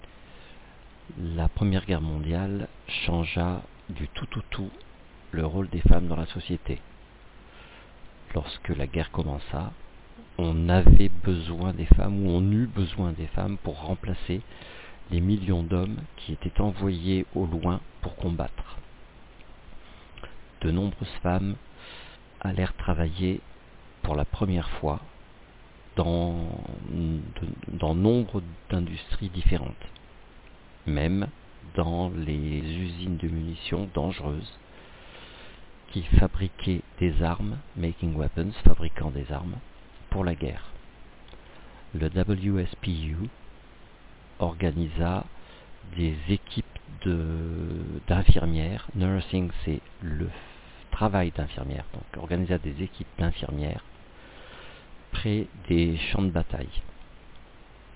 J'ai enregistré une traduction du texte "Suffragettes", à la demande d'une élève.